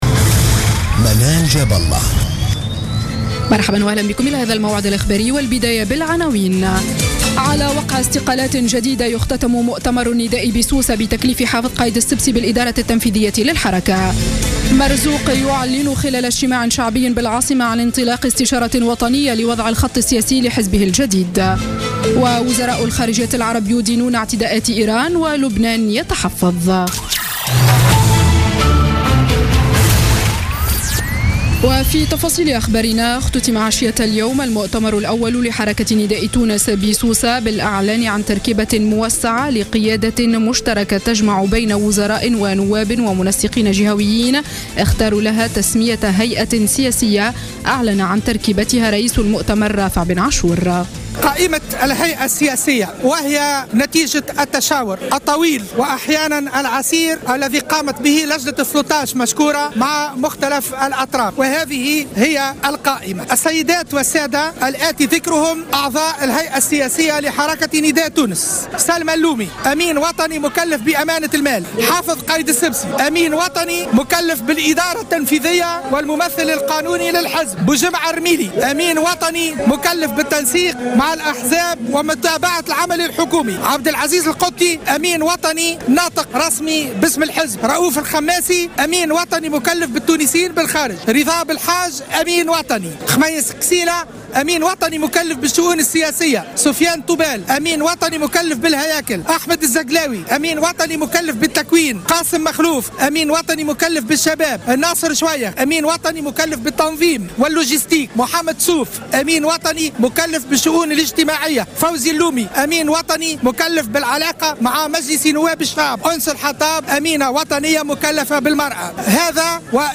Journal Info 19h00 du dimanche 10 janvier 2015